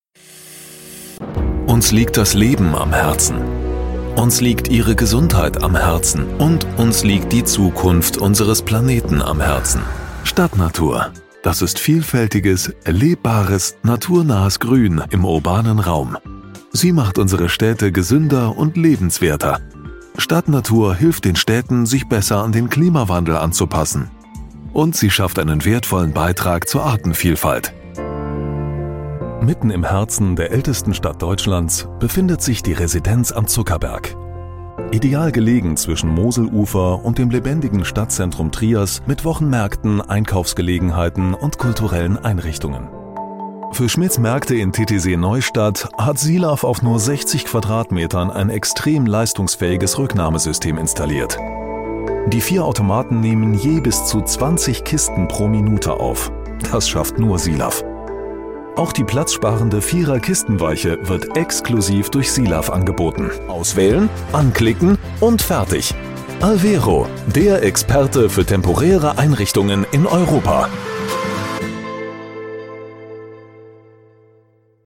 Sprechprobe: Industrie (Muttersprache):
German voice over artist.